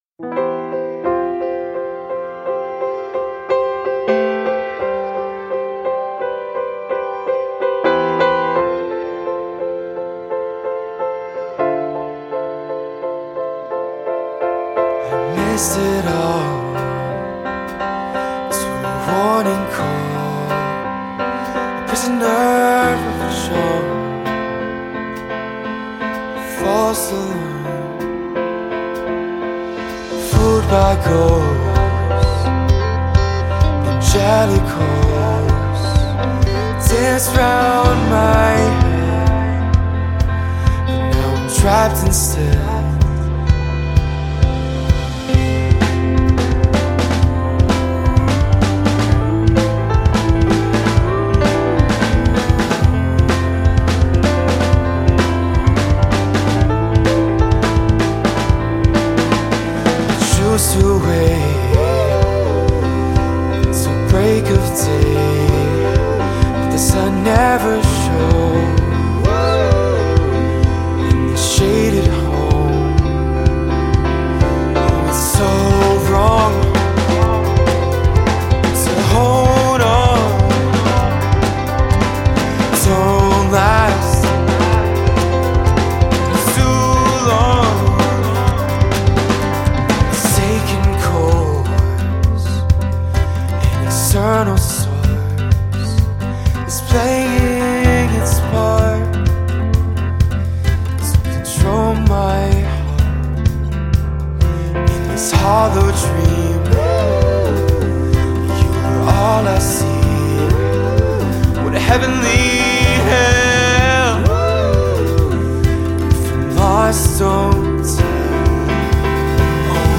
self-produced folk & pop singer-songwriter